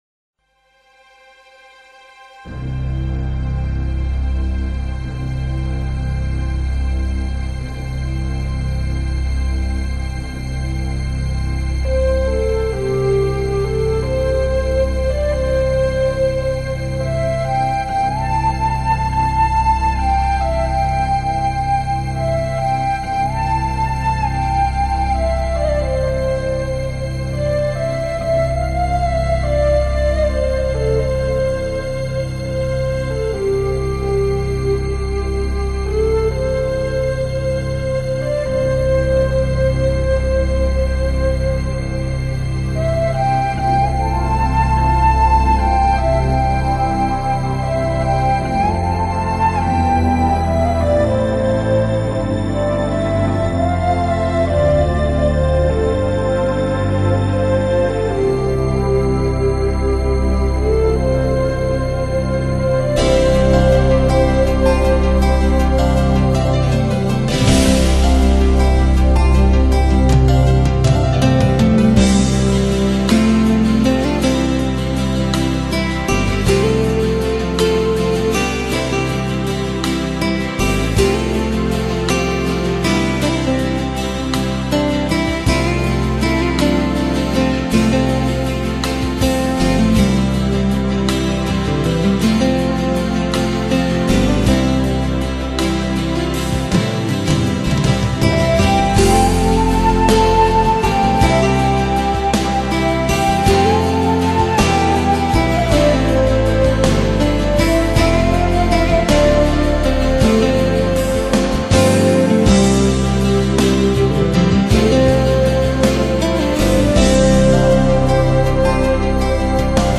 音乐类型: New Age / Celtic